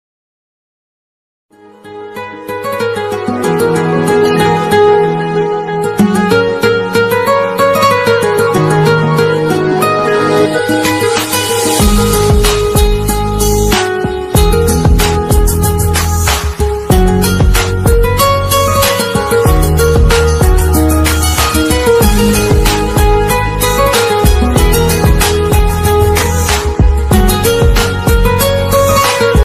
Romantic Song Ringtone